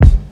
Kick (IN THE MOMENT)(1).wav